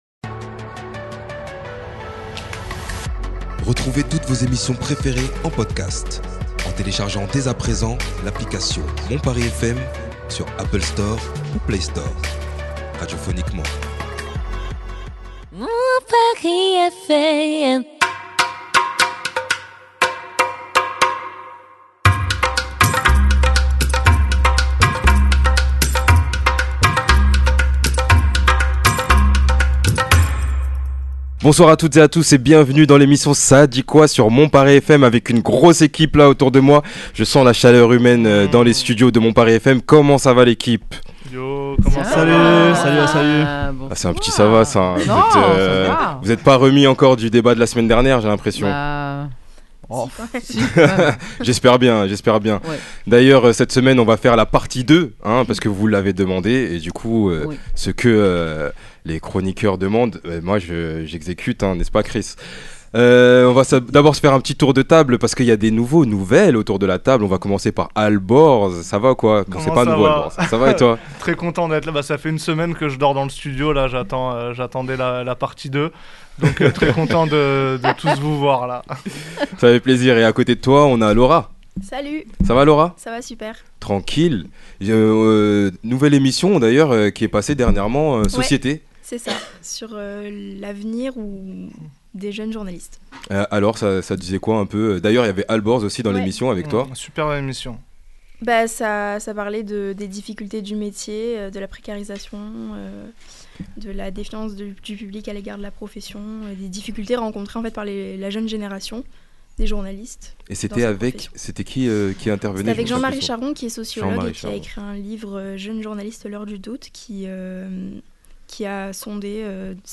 Débat